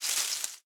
leaves7.ogg